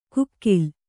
♪ kukkil